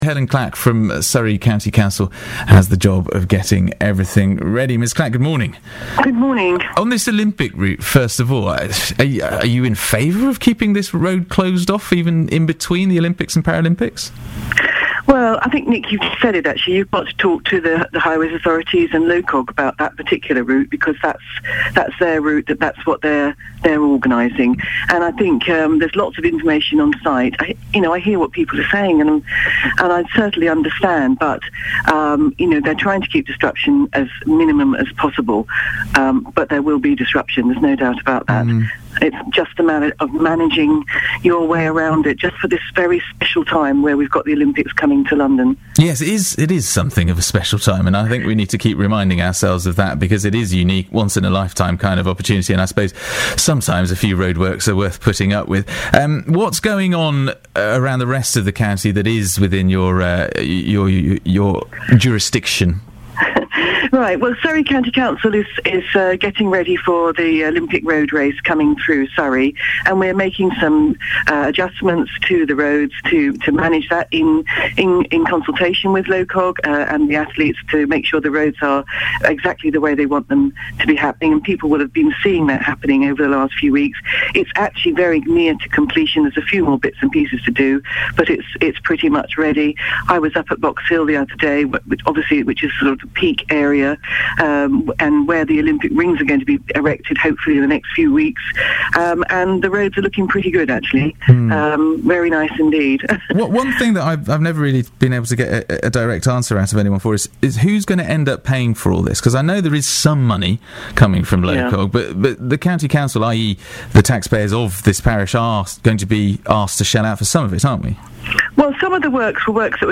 Helyn Clack interviewed on BBC Surrey
Cabinet Member for Community Services and the 2012 Games, Helyn Clack, was interviewed on BBC Surrey today (27 June) about preparations for the Olympic road cycling events coming to Surrey.